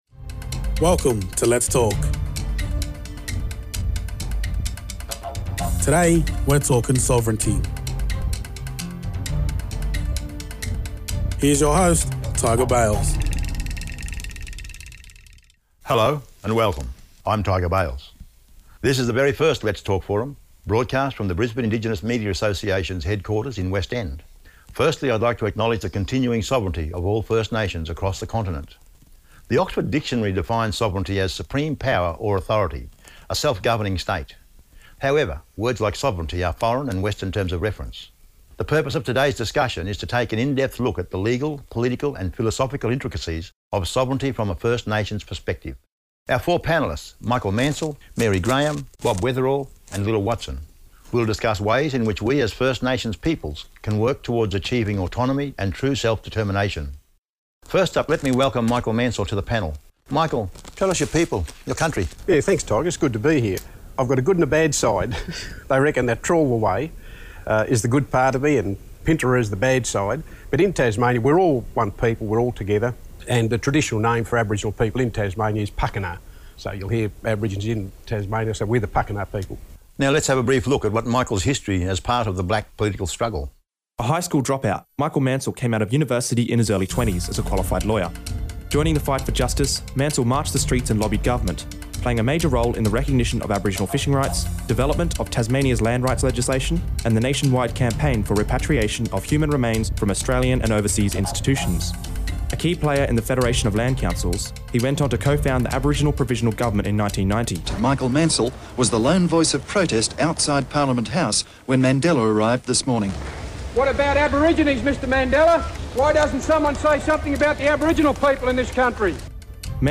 A Panel discussing the Political, Philosophical and legal inctricacies of First Nations’ Sovereignty.